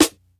Medicated Snare 25.wav